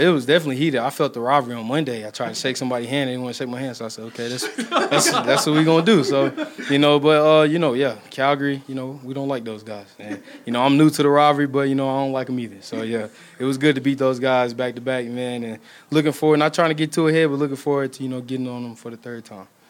EE Rookie Linebacker